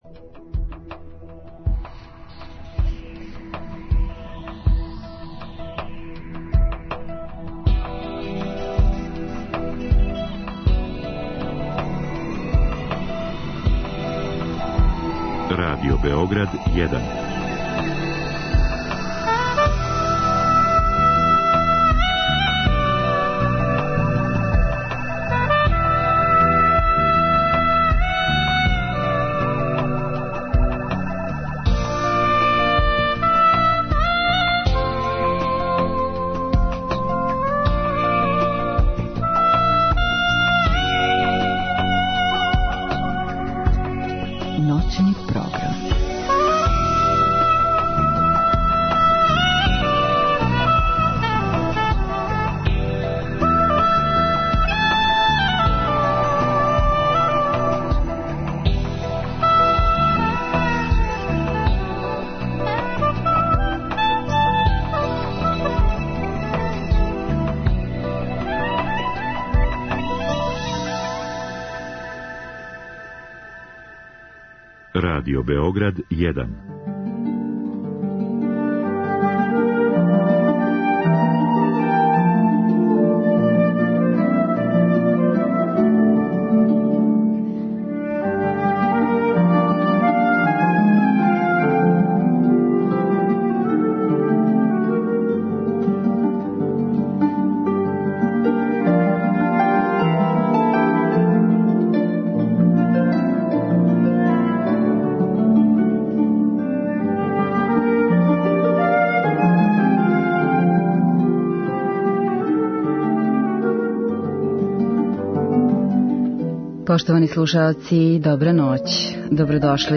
У наставку емисије слушамо композиције Дворжака, Менделсона, Равела, Шумана, Сука, Габријелија и Рахмањинова.